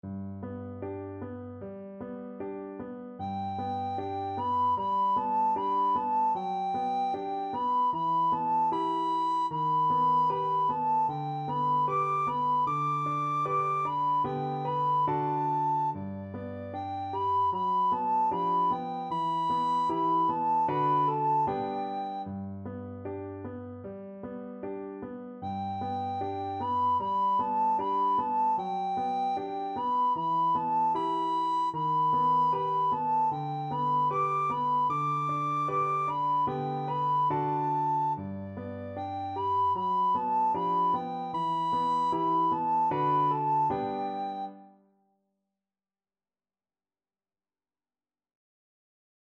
Andante =c.76